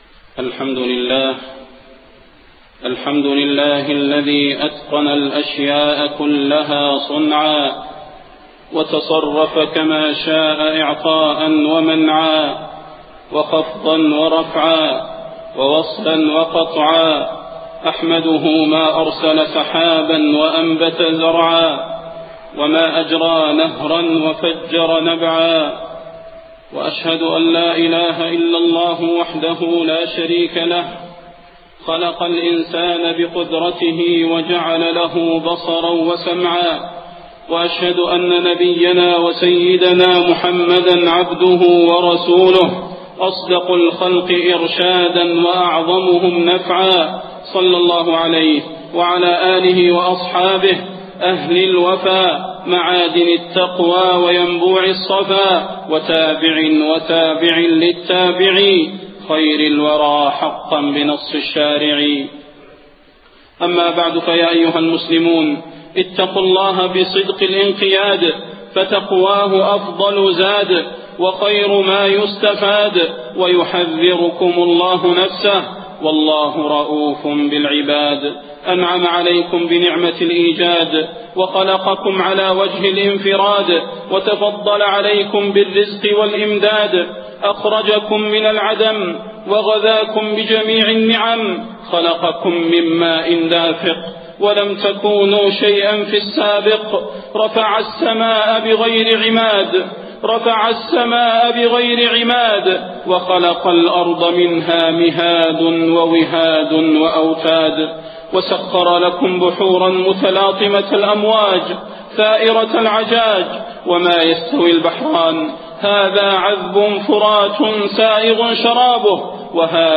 خطبة الاستسقاء - المدينة- الشيخ صلاح البدير
تاريخ النشر ٢٥ محرم ١٤٣١ هـ المكان: المسجد النبوي الشيخ: فضيلة الشيخ د. صلاح بن محمد البدير فضيلة الشيخ د. صلاح بن محمد البدير خطبة الاستسقاء - المدينة- الشيخ صلاح البدير